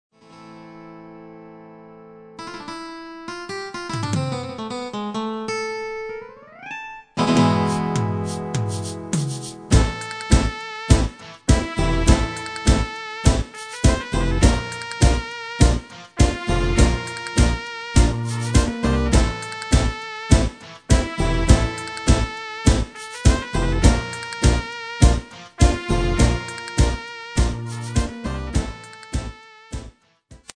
Genre: Dance / Techno / HipHop / Jump
- Vocal harmony tracks
Demo's zijn eigen opnames van onze digitale arrangementen.